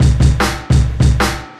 描述：没有许多hihats的me loop
Tag: 150 bpm Breakbeat Loops Drum Loops 275.80 KB wav Key : Unknown